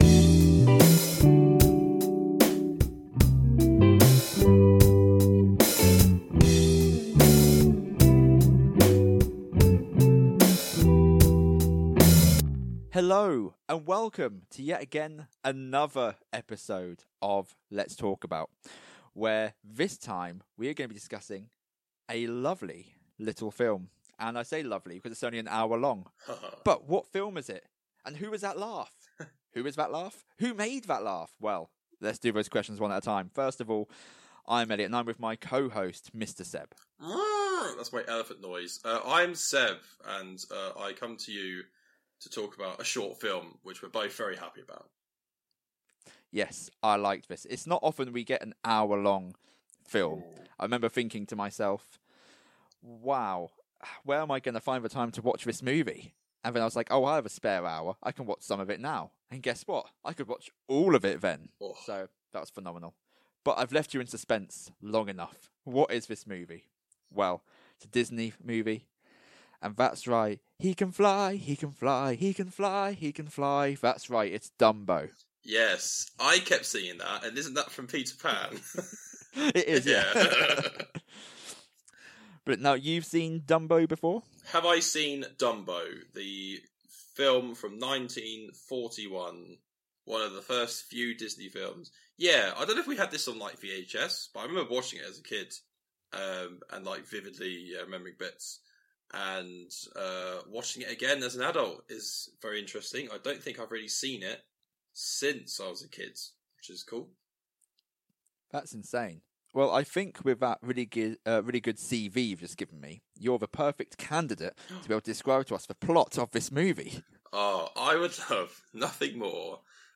Two British Guys (Kinda...) Discuss whatevers on their mind that day, whether it be a film, song or what they had for breakfast.